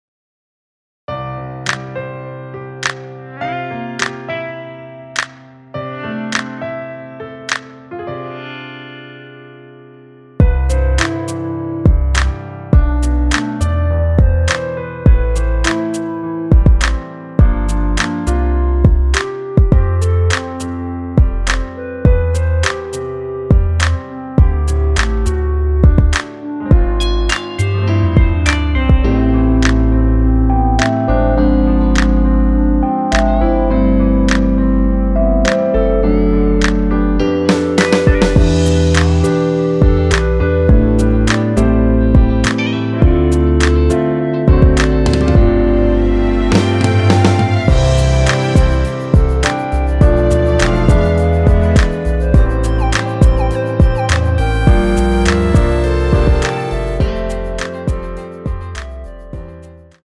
원키 멜로디 포함된 MR입니다.(미리듣기 참조)
앞부분30초, 뒷부분30초씩 편집해서 올려 드리고 있습니다.